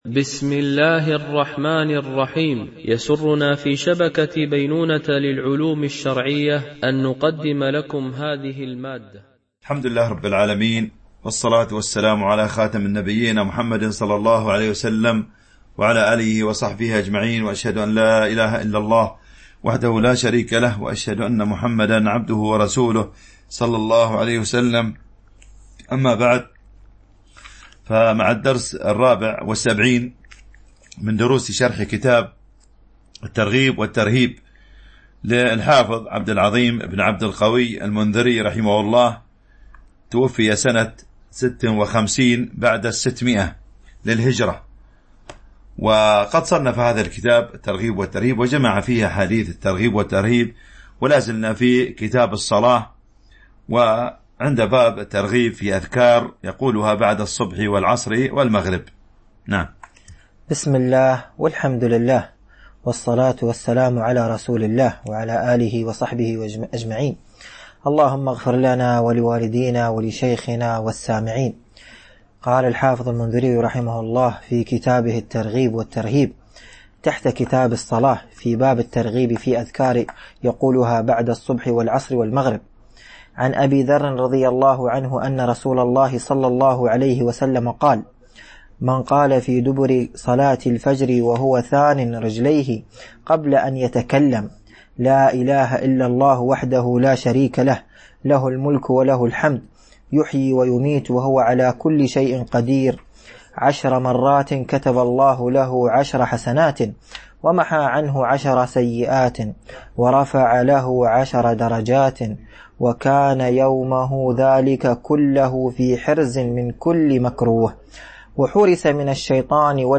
شرح كتاب الترغيب والترهيب - الدرس 74 ( كتاب الصلاة .الحديث 682 - 685)
MP3 Mono 22kHz 32Kbps (CBR)